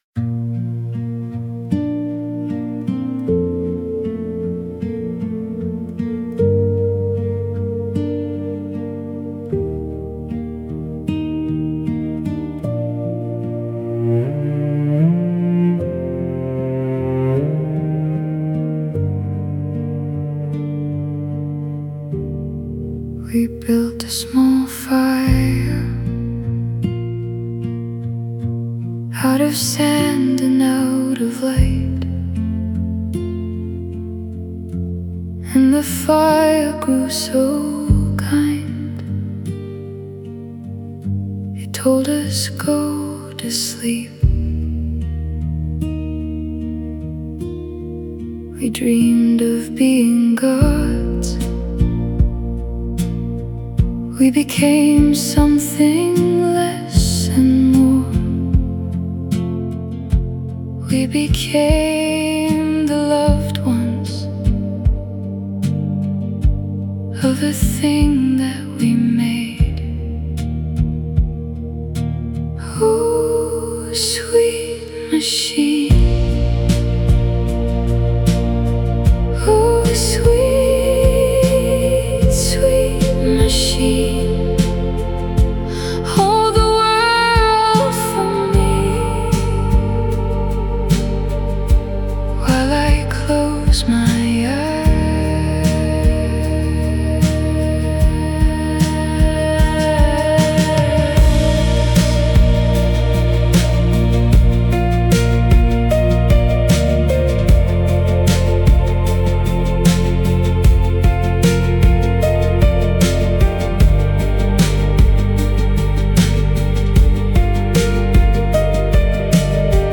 Sweet Machine — the song that goes with this essay (generated with Suno from the lyrics below)